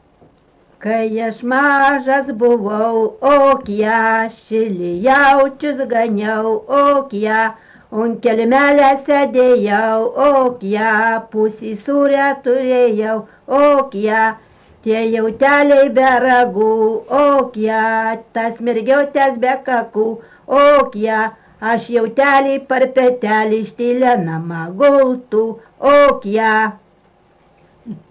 daina, vestuvių
Jonava Bagdoniškis
Atlikimo pubūdis vokalinis